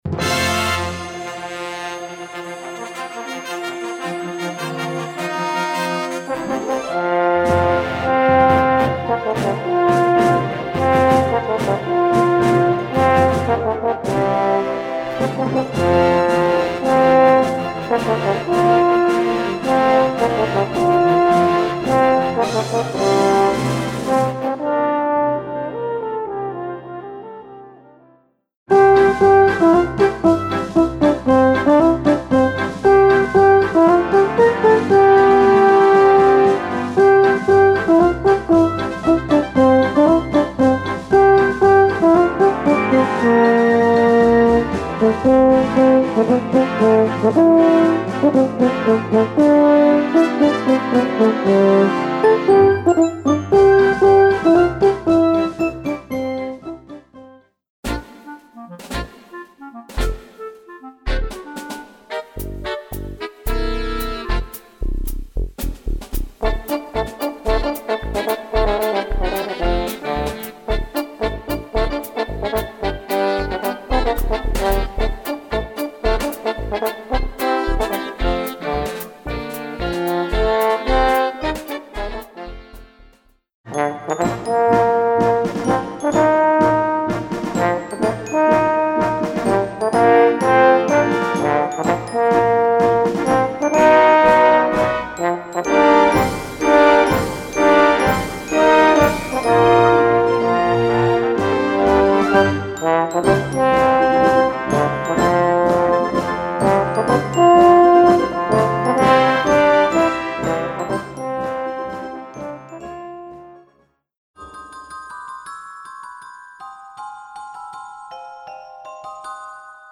Voicing: French Horn w/ Audio